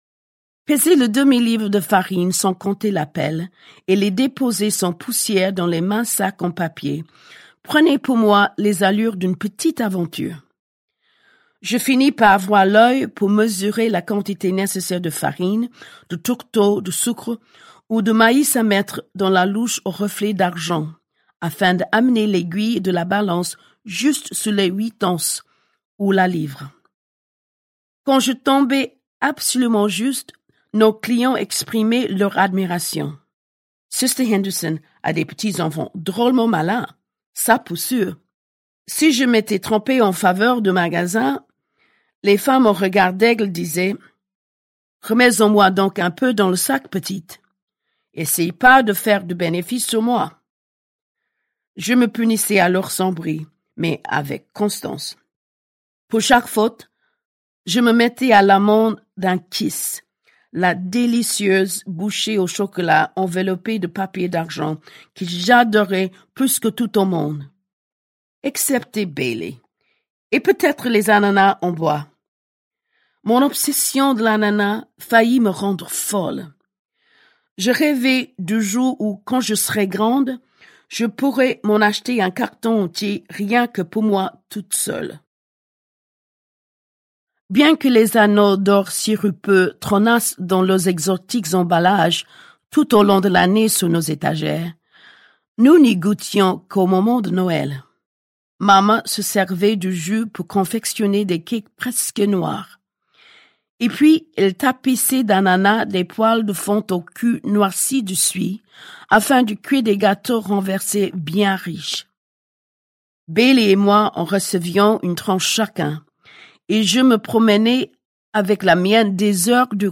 Le livre audio Je sais pourquoi chante l’oiseau en cage de Maya Angelou lu par Barbara Hendricks (des femmes-Antoinette Fouque, 2022) est lauréat du Prix du livre audio 2023 France CultureLire dans le noir dans la catégorie Non Fiction.
Interprète majeure du chant lyrique, connue également pour son indéfectible engagement humanitaire, la soprano Barbara Hendricks a accepté de lire en français ce récit, tour à tour émouvant, hilarant et dramatique dont elle connait des passages par cœur, avec la passion qui l’anime depuis son adolescence pour la personne et l’œuvre de Maya Angelou, porte-parole des droits civiques des noirs d’Amérique, modèle de liberté, d’audace, de volonté et de courage.
Livre audio événement, Je sais pourquoi chante l’oiseau en cage réunit deux légendes, à travers le texte poignant de la poétesse américaine Maya Angelou et l’interprétation habitée de la cantatrice Barbara Hendricks.
angelou-maya-je-sais-pourquoi-chante-l-oiseau-en-cage-barbara-hendricks.mp3